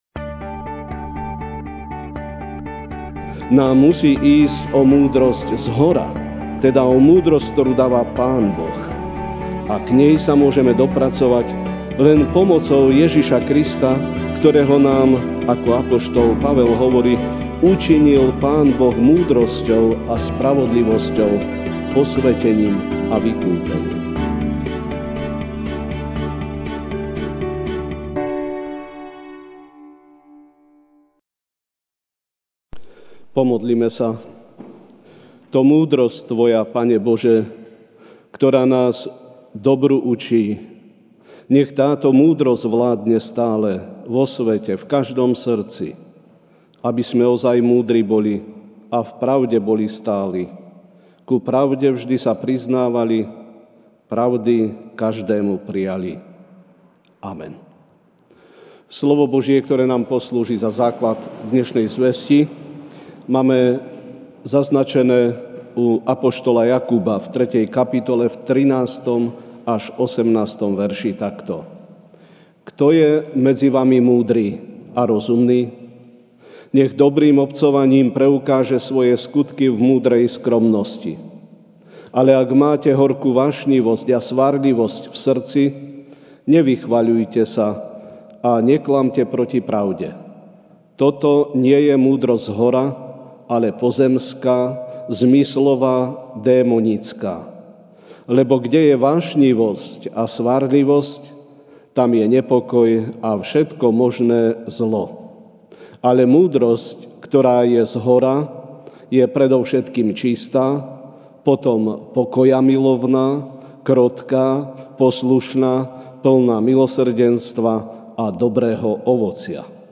aug 11, 2019 Výstraha pred zvodmi MP3 SUBSCRIBE on iTunes(Podcast) Notes Sermons in this Series Ranná kázeň: Výstraha pred zvodmi (Jk 3, 13-18) Kto je medzi vami múdry a rozumný?